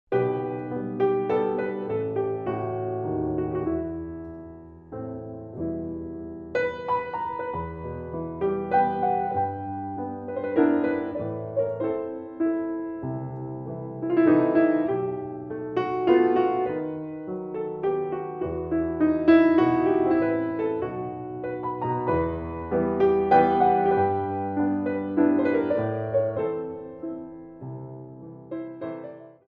Ballet Music for All Level Classes
Solo Piano
Slow Waltzes